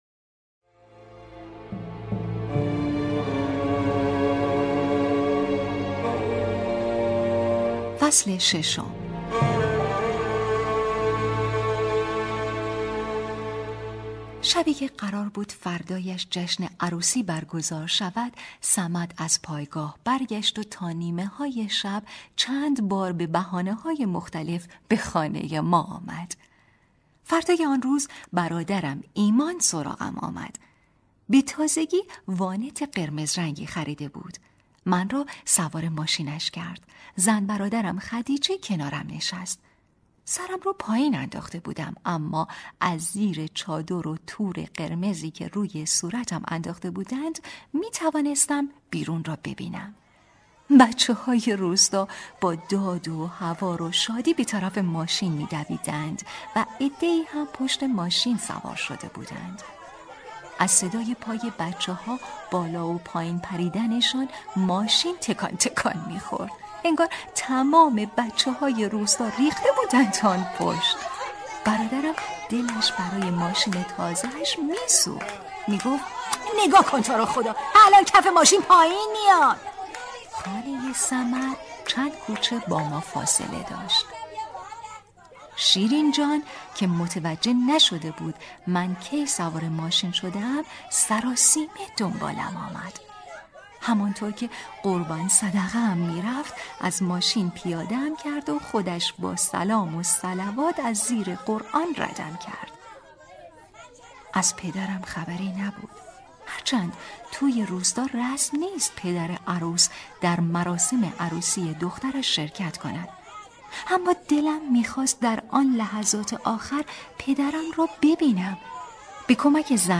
کتاب صوتی | دختر شینا (05)